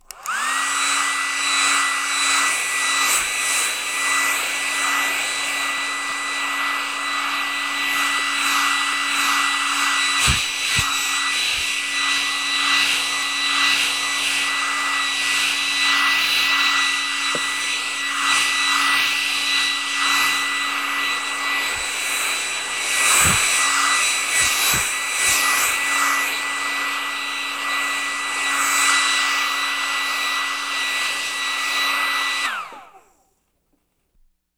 hairdry.wav